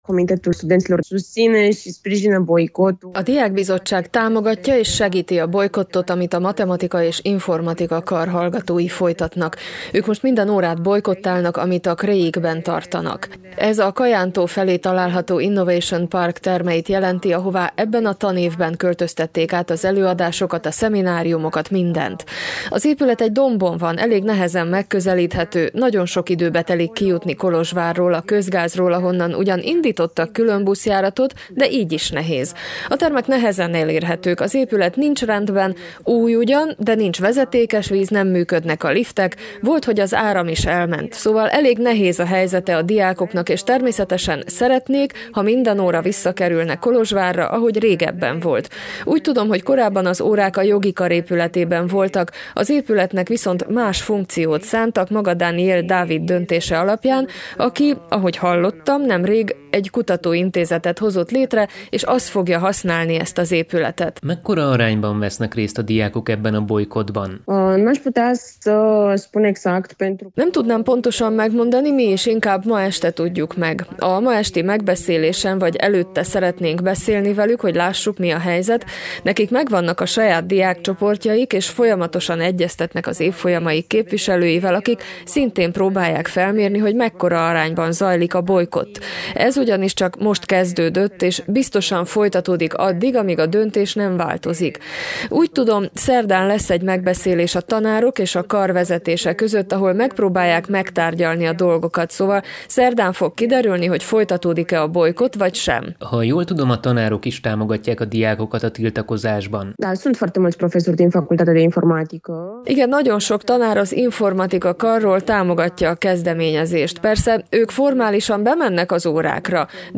A kifogásolt körülményekről az egyetemtől független Kolozsvári Diákbizottság egyik alapítója nyilatkozott rádiónknak.